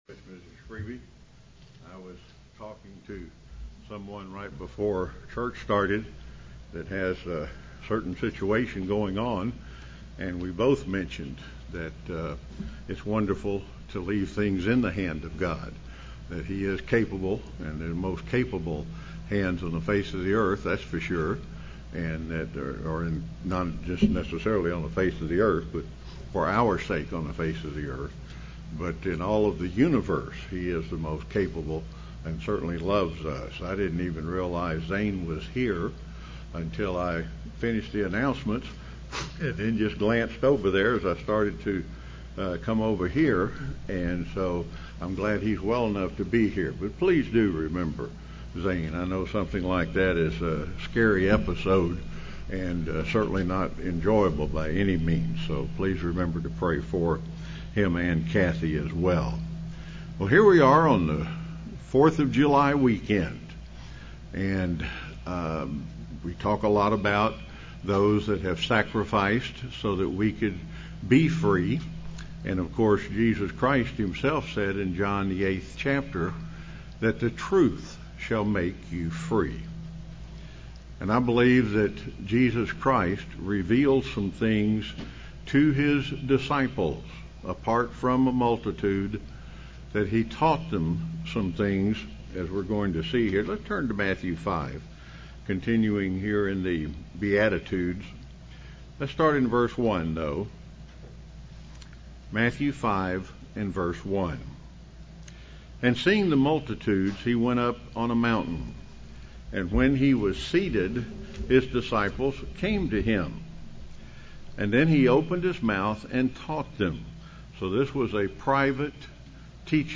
Sermons
Given in Rome, GA